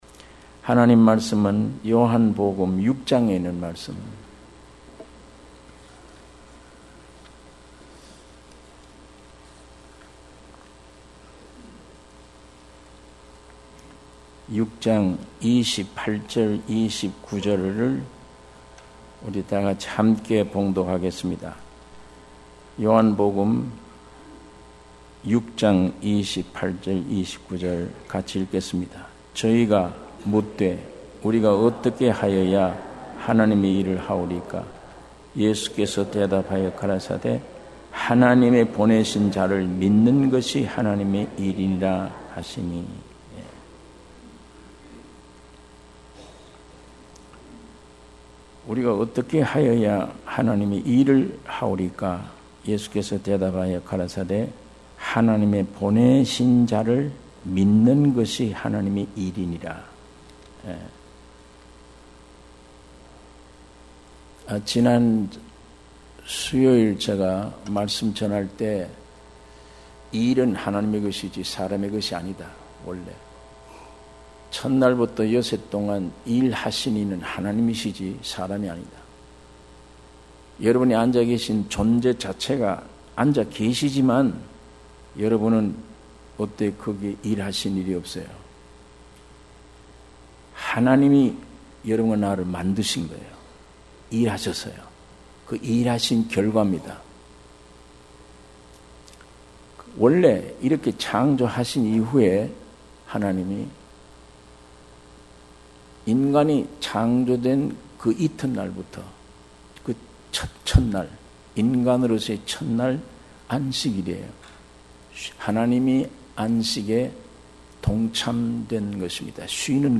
주일예배 - 요한복음 6장 28-29절